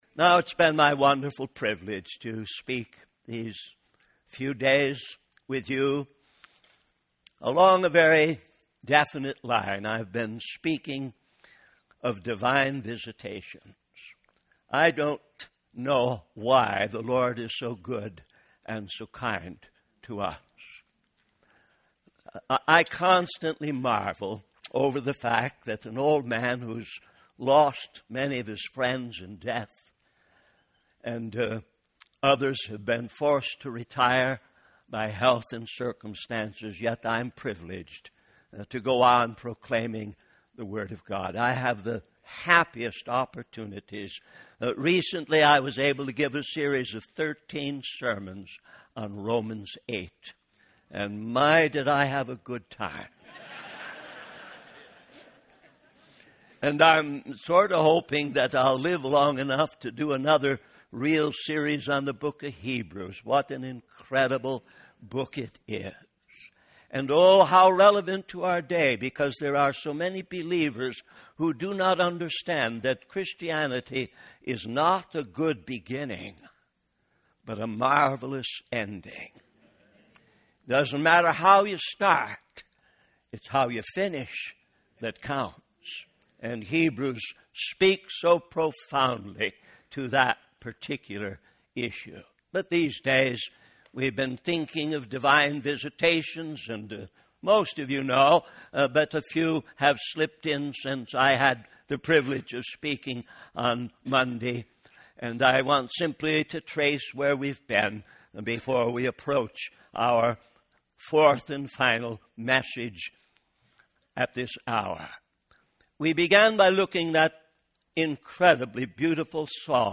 In this sermon, the preacher discusses the concept of repentance and the opportunity for sinners to turn to God. He references five scriptures, including the book of Jude, to emphasize the importance of repentance and the power of God in bringing about revival.